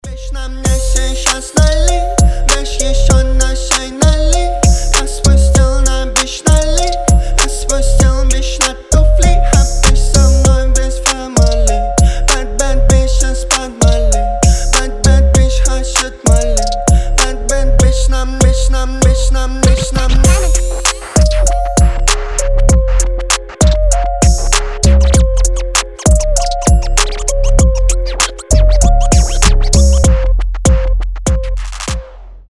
• Качество: 320, Stereo
Хип-хоп
качающие
грубые